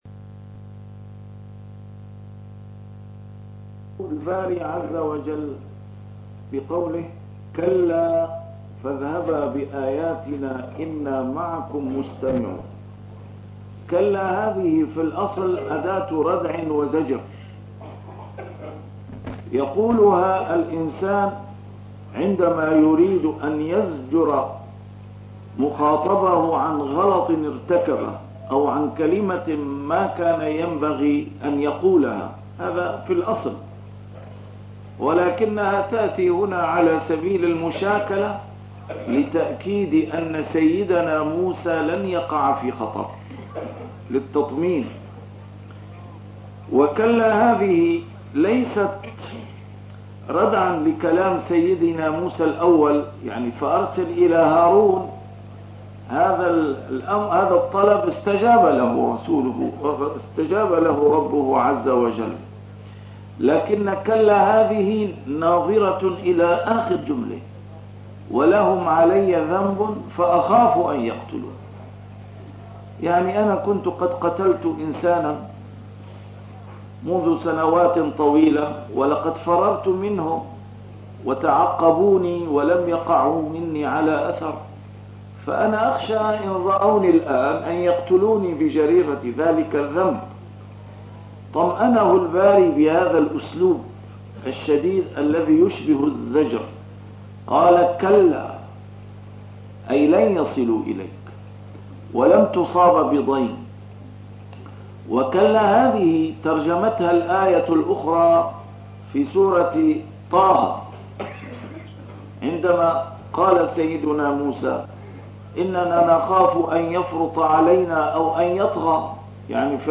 A MARTYR SCHOLAR: IMAM MUHAMMAD SAEED RAMADAN AL-BOUTI - الدروس العلمية - تفسير القرآن الكريم - تسجيل قديم - الدرس 225: الشعراء 015-022